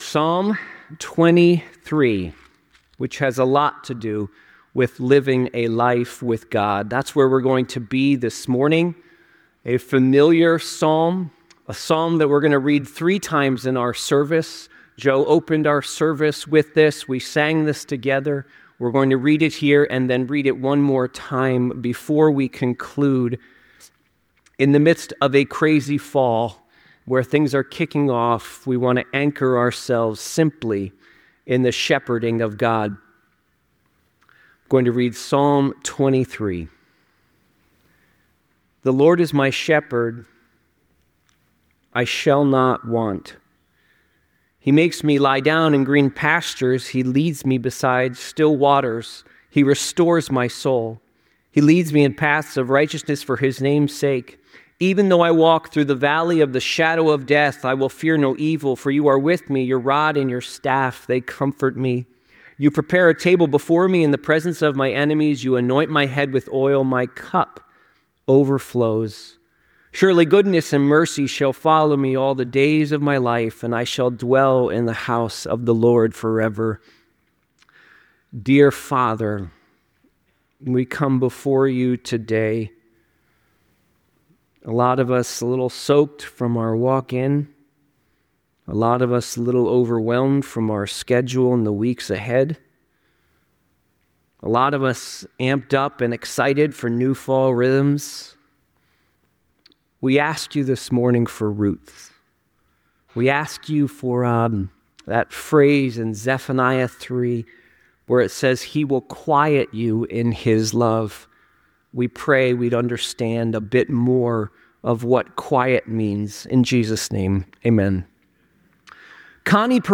The sermon focuses on Psalm 23, emphasizing the importance of living a life anchored in God.